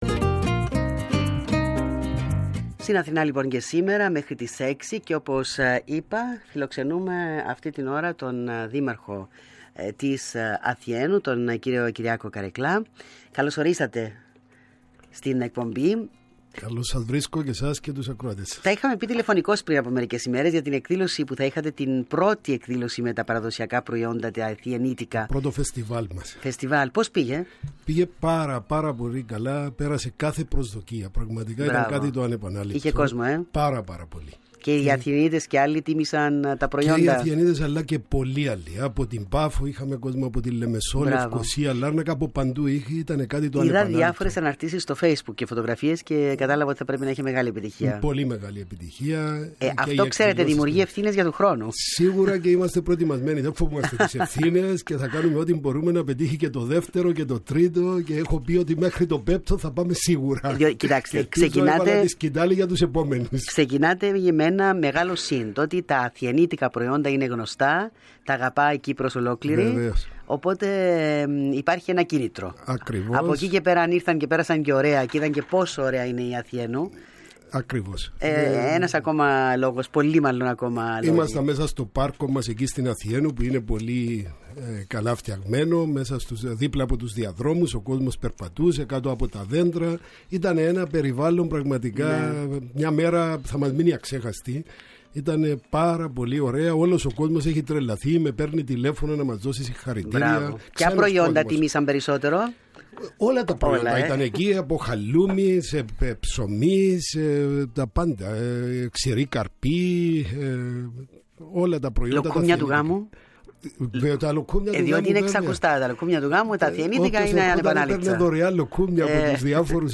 Συνέντευξη του Δημάρχου
kareklas_sinenteuksi.mp3